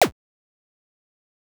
8bit_FX_Shot_01_02.wav